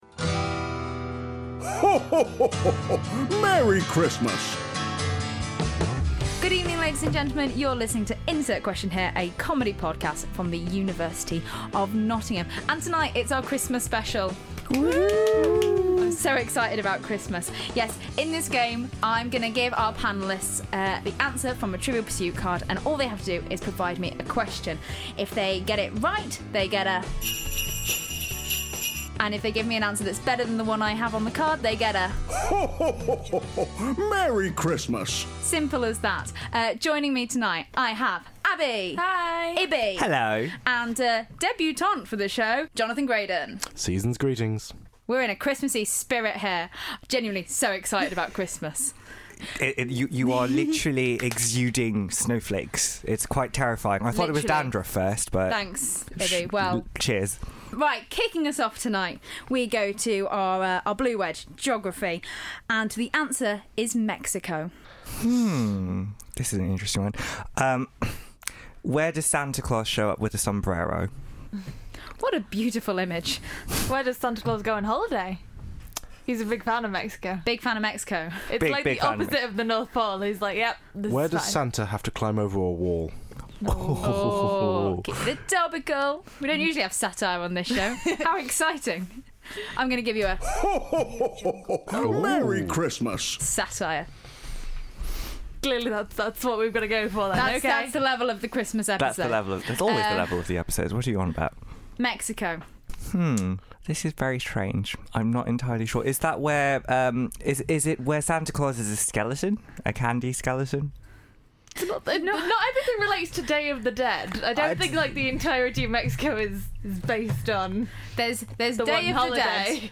4 students. 1 pack of children's Trivial Pursuit cards.